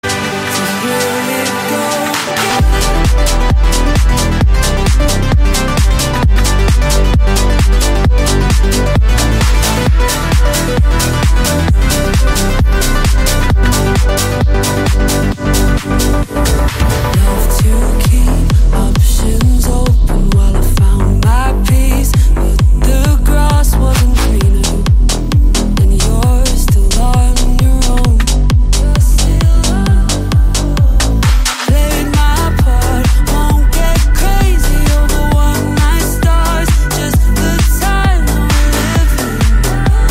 Categoria Eletronicas